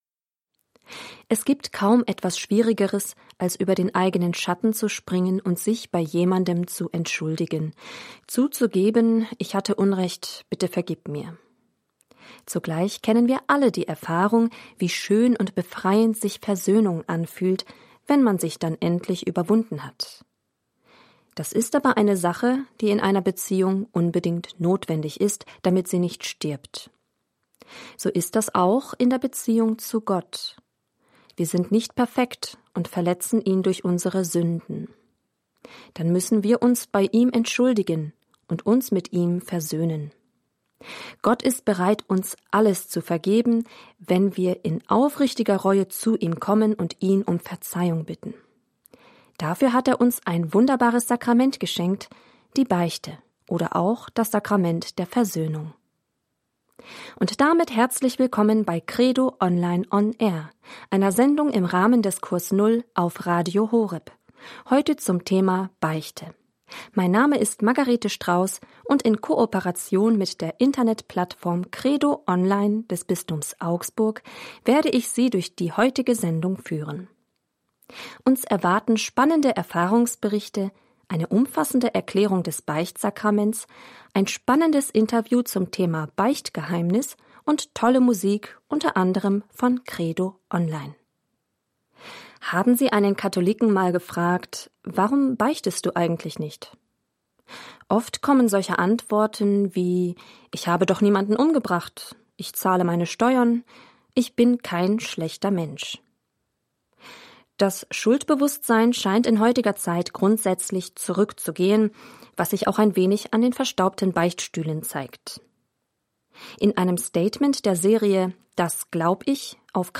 Sendung vom 27. November 2021 bei Radio Horeb
In der Sendung vom 27. November wird das Beichtsakrament umfassend erklärt, es gibt ein Interview zum Thema Beichtgeheimnis und junge Menschen berichten über ihre Erfahrungen mit der Beichte.